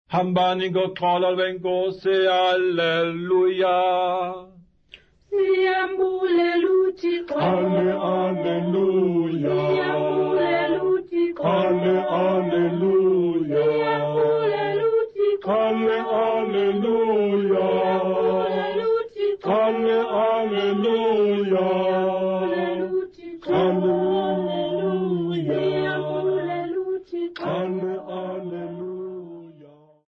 Church Choir
Folk music Africa
Sacred music South Africa
Church music South Africa
Field recordings South Africa
field recordings
Unaccompanied new Xhosa prayer and hymn 250.